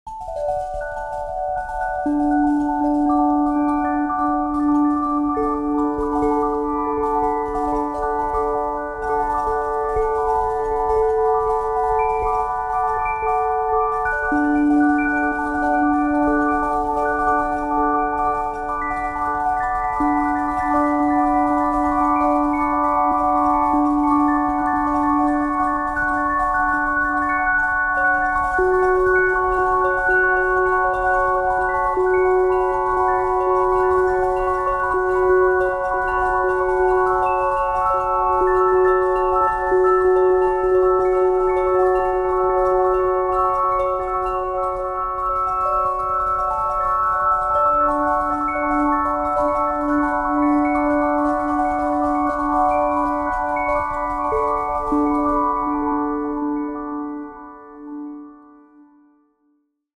mélancolique, joyeux, apaisant, nostalgique